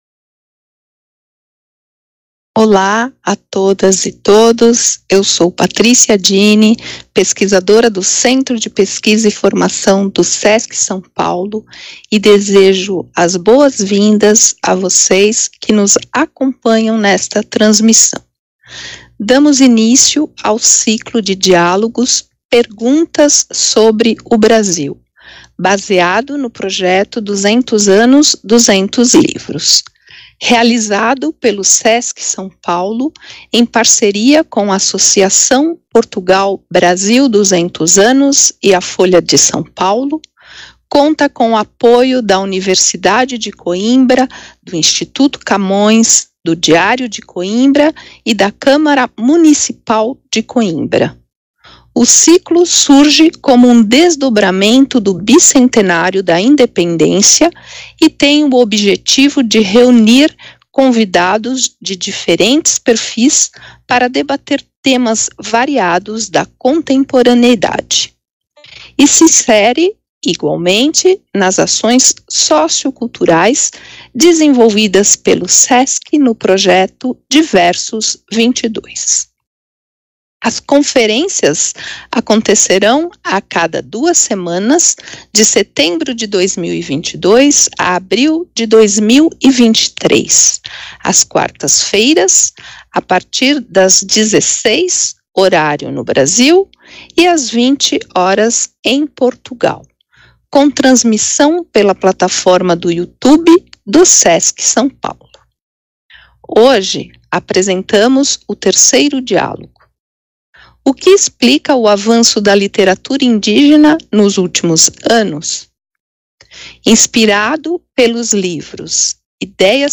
“Perguntas sobre o Brasil” – DIÁLOGO 3 Os autores Eliane Potiguara e Kaká Werá são os convidados do debate organizado pelo Sesc-SP, pela Associação Portugal Brasil 200 anos e pela Folha de S. Paulo O Centro de Pesquisa e Formação (CPF) do Sesc São Paulo, a Associação Portugal Brasil 200 anos (APBRA) e a Folha promovem mais um debate do ciclo Perguntas […]